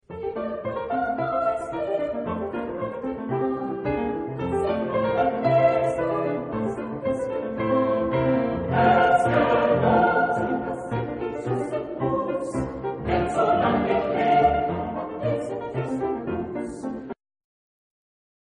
SATB (4 voix mixtes) ; Partition choeur seul ; Partition édition scientifique ; Partition complète.
Romantique. Pièce chorale.